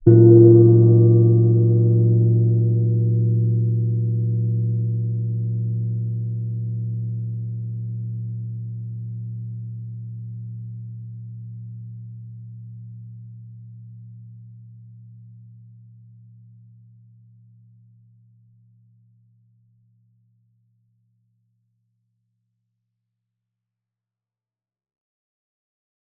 jing_cotton_ord-A0-ff.wav